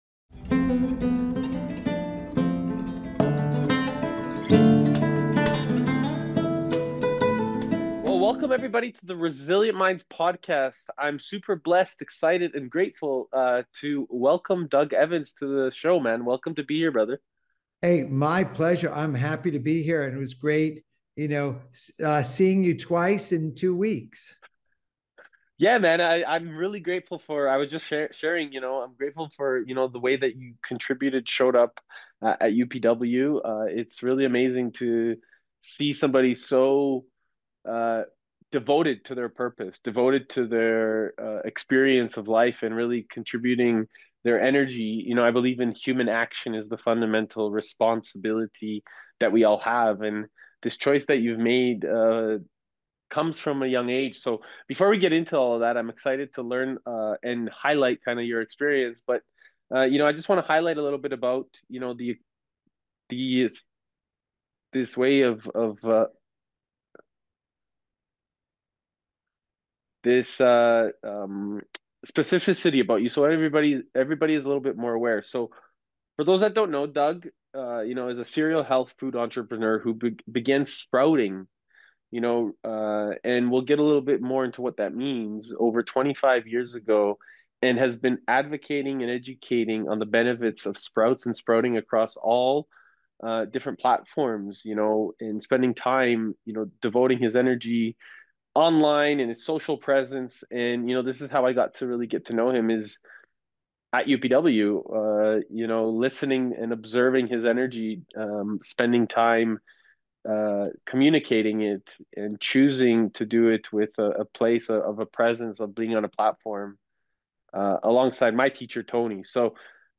In this deeply human conversation